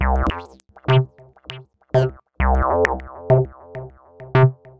UR 303 (min loop) 1.wav